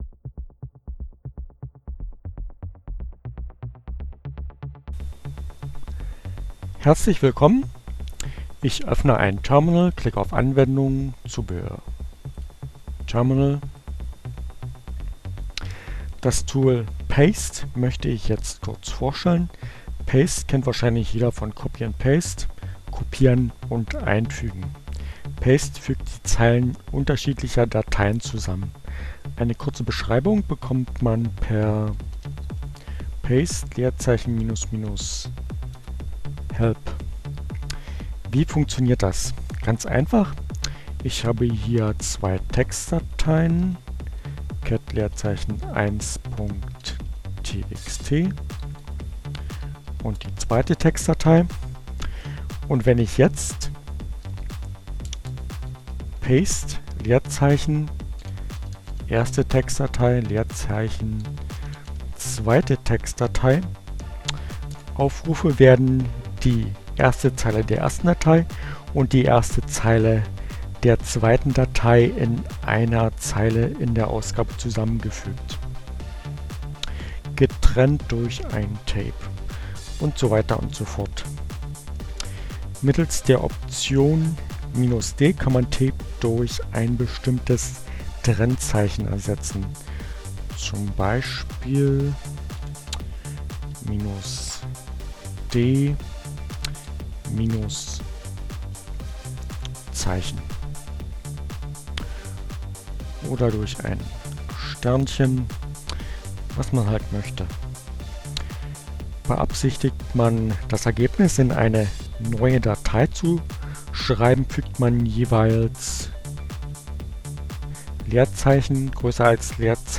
Tags: CC by-sa, Gnome, Linux, Neueinsteiger, Ogg Theora, ohne Musik, screencast, ubuntu, paste